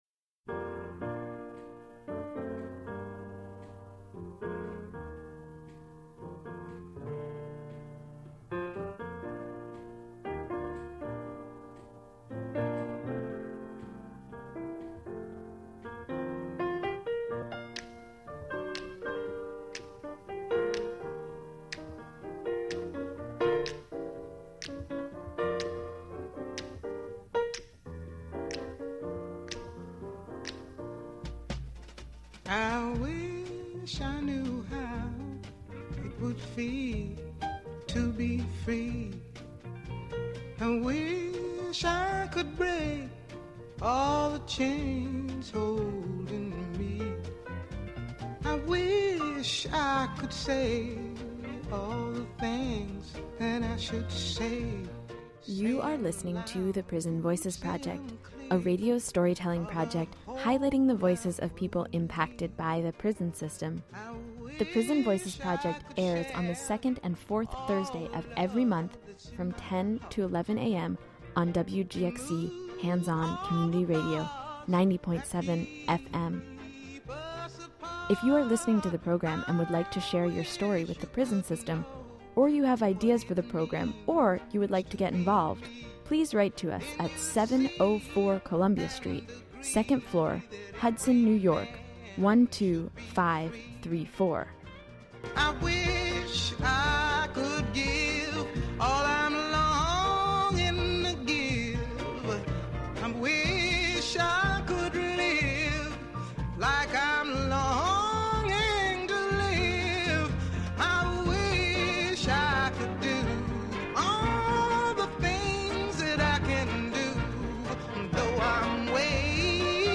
This broadcast focuses on residency restrictions faced by individuals following conviction of a sex offense. The conversation focuses on a new policy in New York state which authorizes the Department of Corrections and Community Services to detain people past their prison sentence if they are convicted of certain categories of sex offenses when they cannot find housing at least 1,000 feet outside of a school zone. Interviews with attorneys from Manhattan's Legal Aid Society, Center for Appellate Litigation, and the Office of the Appellate Defender are featured.